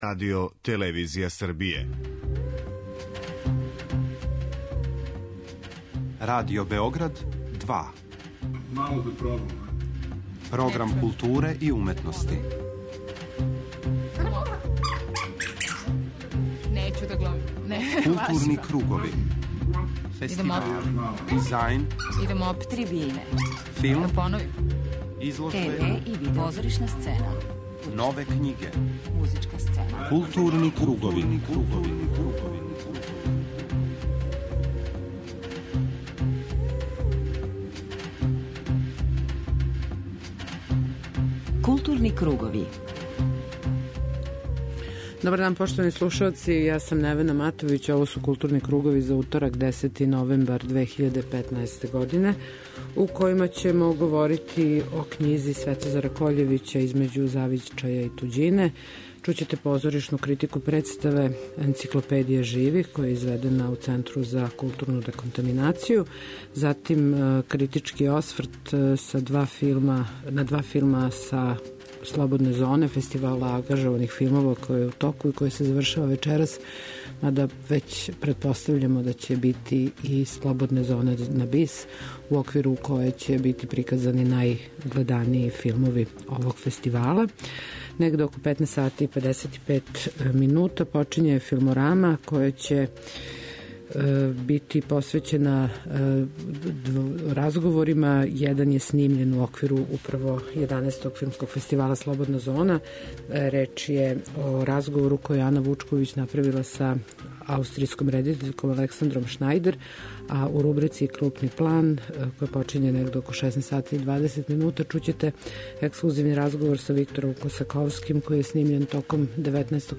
У рубрици Крупни план чућете ексклузивни интервју са редитељем Виктором Косаковским, снимљен током трајања 19. Међународног фестивала докуметарног филма у Јихлави.